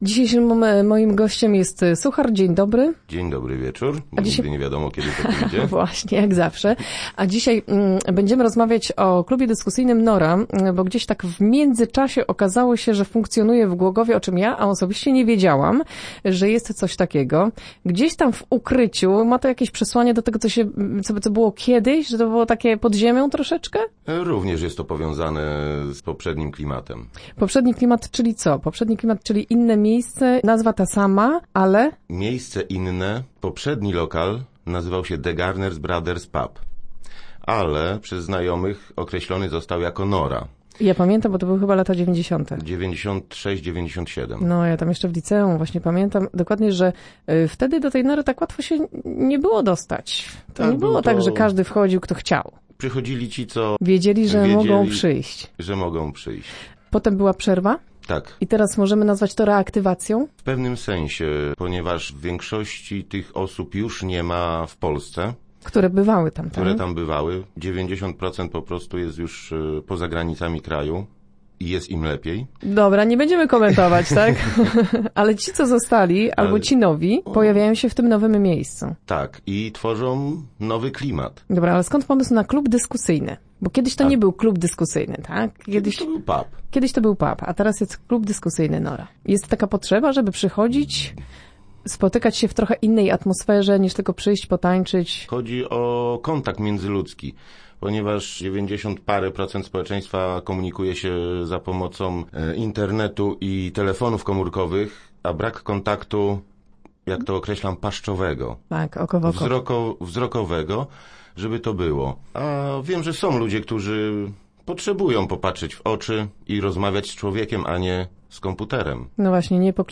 O tym nasz gość opowiedział w piątkowych Rozmowach Elki.